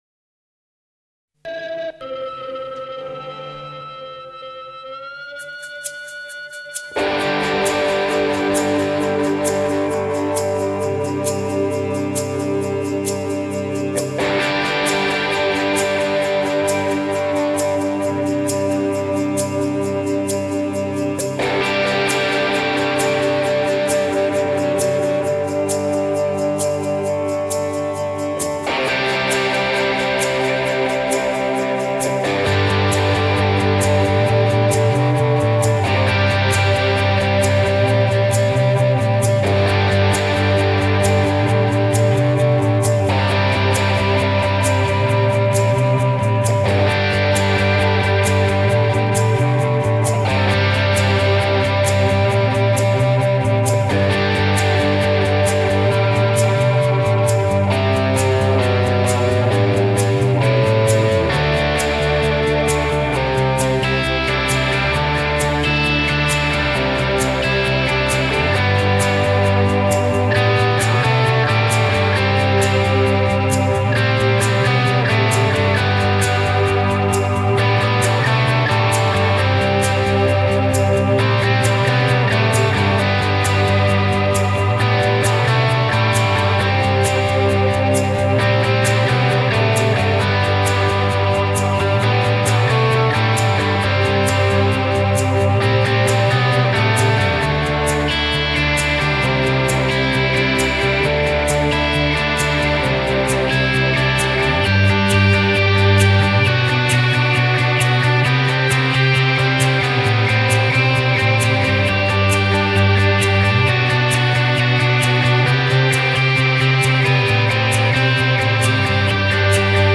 BPM : 132
Tuning : E
Without vocals